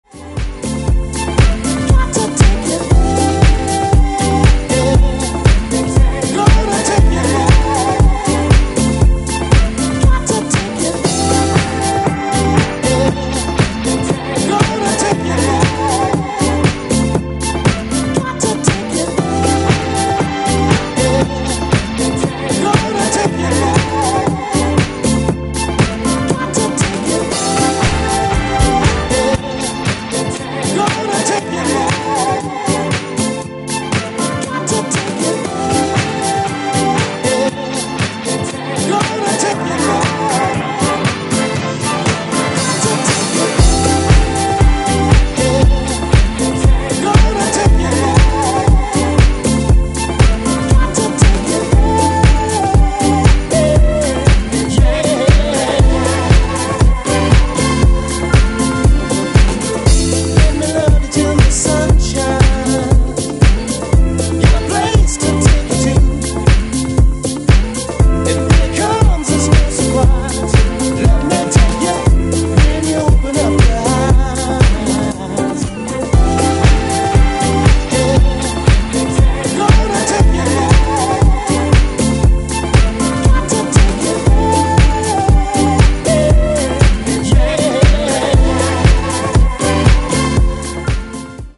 ジャンル(スタイル) DISCO HOUSE / DEEP HOUSE / RE-EDIT